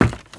STEPS Wood, Creaky, Run 28.wav